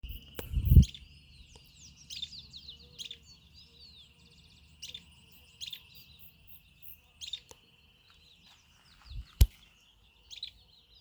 Barullero (Euscarthmus meloryphus)
Nombre en inglés: Fulvous-crowned Scrub Tyrant
Localidad o área protegida: Toro negro
Condición: Silvestre
Certeza: Observada, Vocalización Grabada